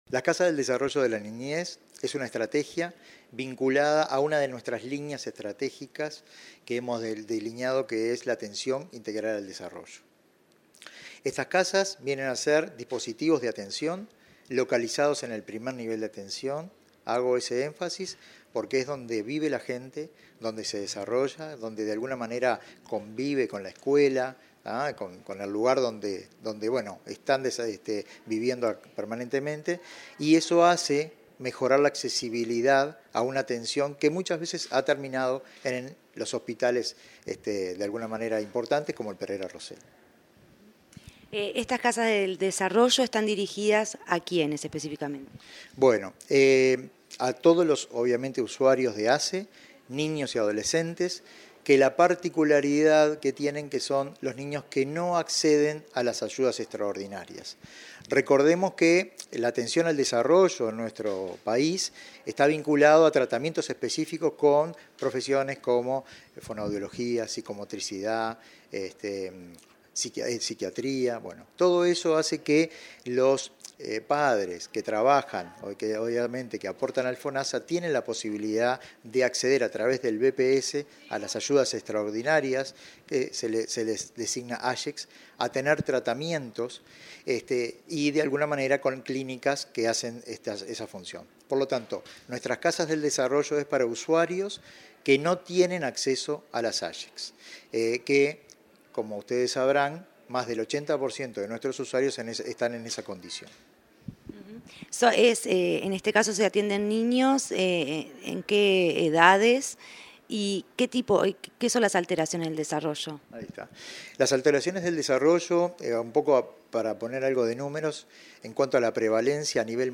Entrevista al director del Área de Niñez y Adolescencia de ASSE, Ignacio Ascione